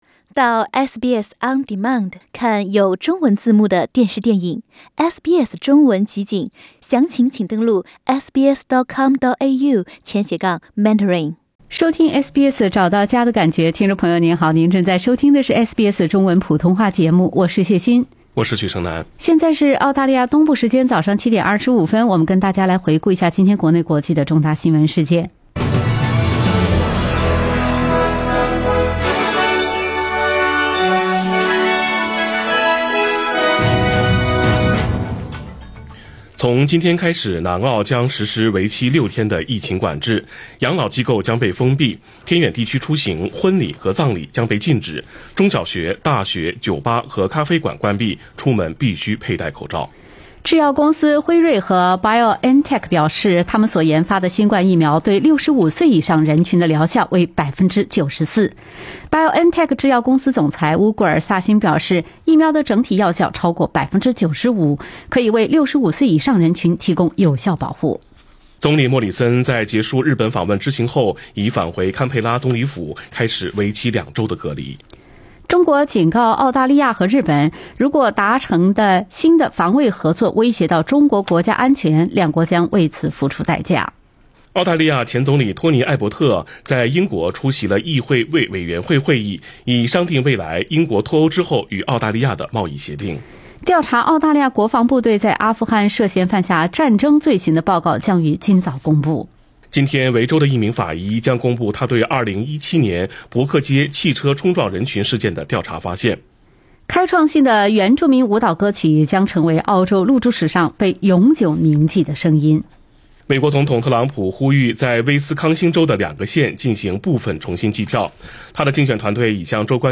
SBS早新闻（11月19日）